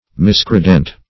Search Result for " miscredent" : The Collaborative International Dictionary of English v.0.48: Miscredent \Mis*cre"dent\, n. [Pref. mis- + credent.